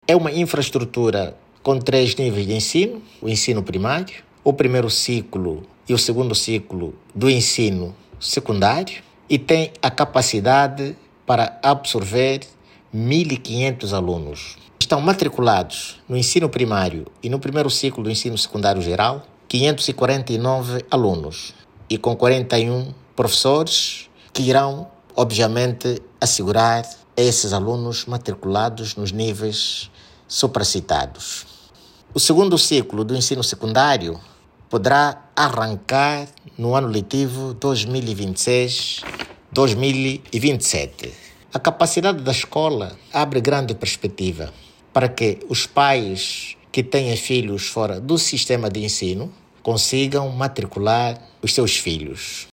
O sector de educação do município de Belas, está mais reforçado, com a inauguração na comuna do Cabolombo, do Complexo Escolar Madre Concepción Dolcet. A infraestrutura composta por 18 salas de aula, vai proporcionar um ambiente de aprendizagem digno e inclusivo, como avança Levítico Kiala diretor municipal de educação de Belas.